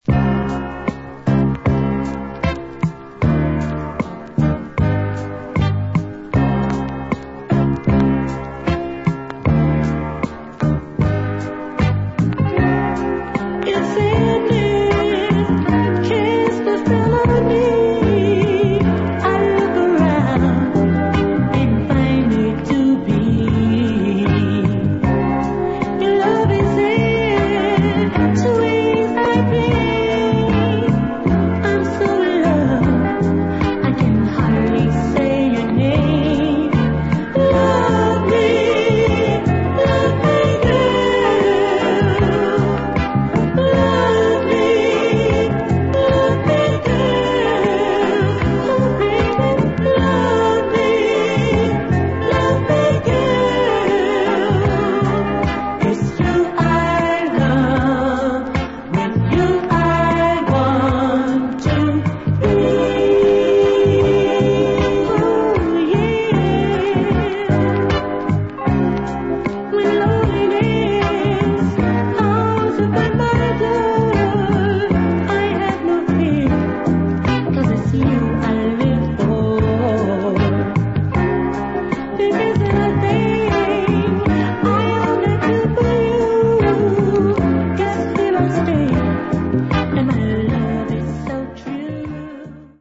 Group Soul